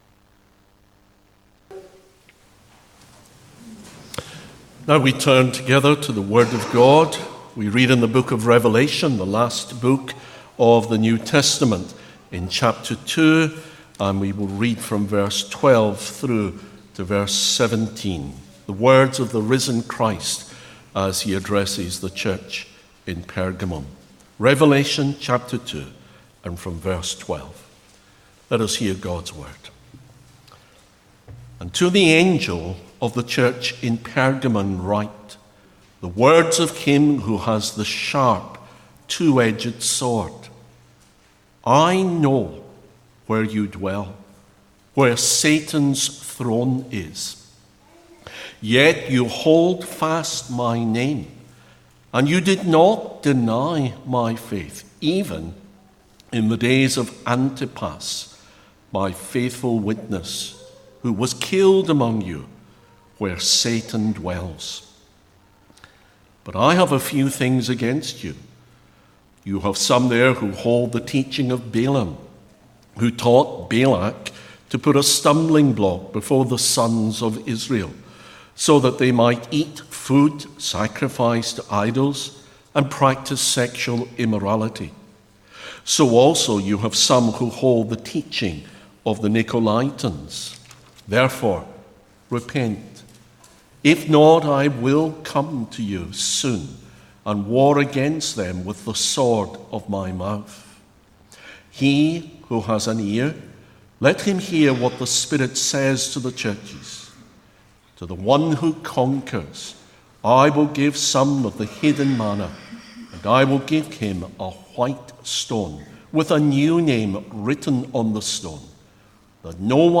Antipas, a Faithful Witness | SermonAudio Broadcaster is Live View the Live Stream Share this sermon Disabled by adblocker Copy URL Copied!